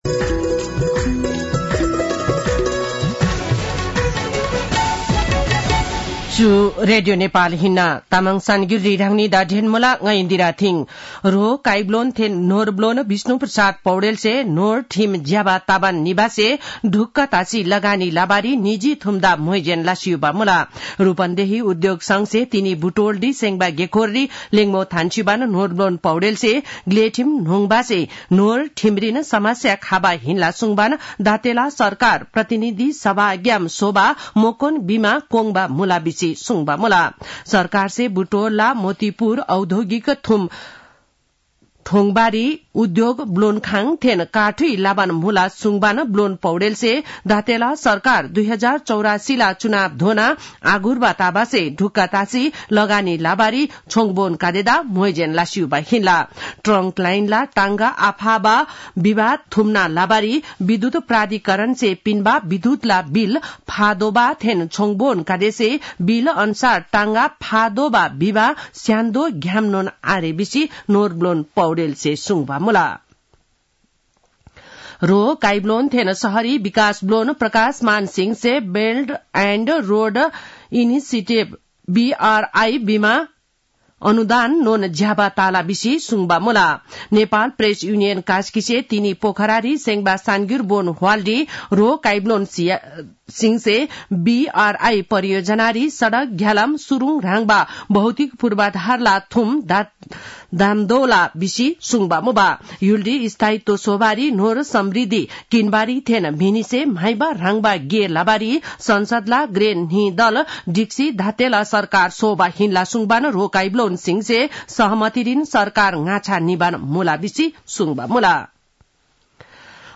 तामाङ भाषाको समाचार : १६ मंसिर , २०८१